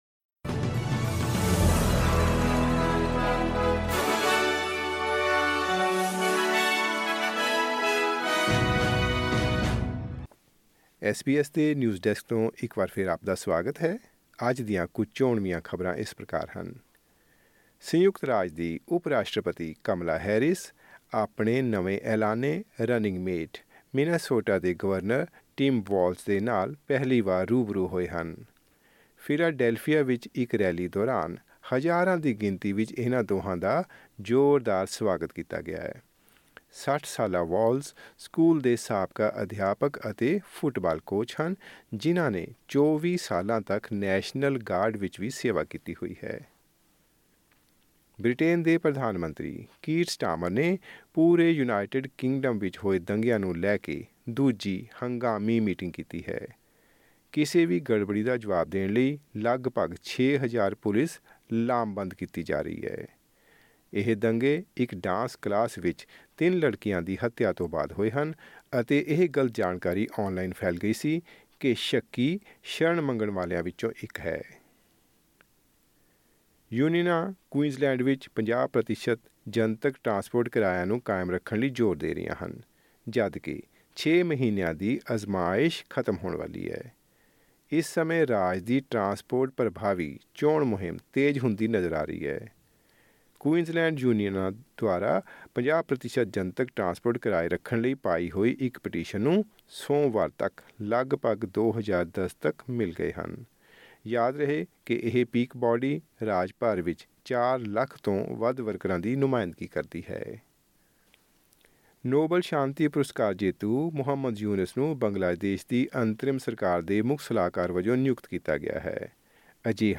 ਐਸ ਬੀ ਐਸ ਪੰਜਾਬੀ ਤੋਂ ਆਸਟ੍ਰੇਲੀਆ ਦੀਆਂ ਮੁੱਖ ਖ਼ਬਰਾਂ: 7 ਅਗਸਤ 2024